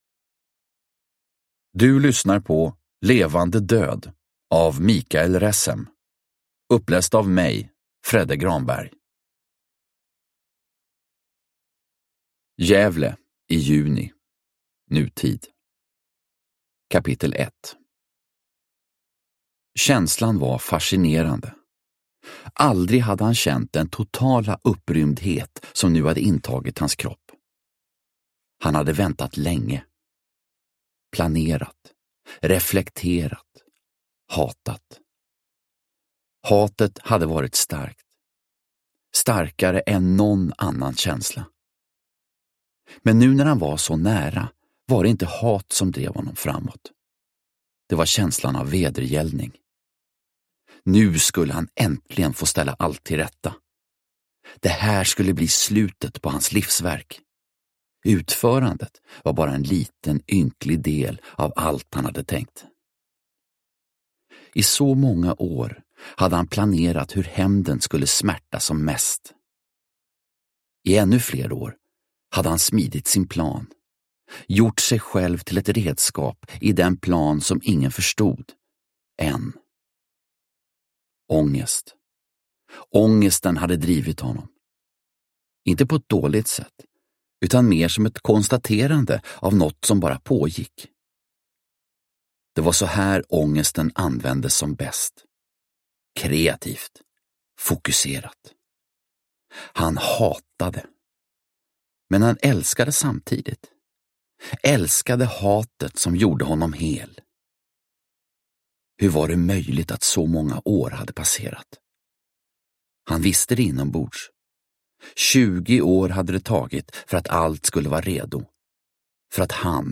Levande död – Ljudbok
Deckare & spänning Njut av en bra bok